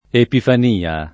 epifan&a] s. f. — anche con E- maiusc. come nome della festa — con questo valore, nell’uso pop. più antico, anche pifania [pifan&a]: il dì della pasqua della Pifania [il d& ddella p#SkUa della pifan&a] (M. Villani) — sim. il top. stor.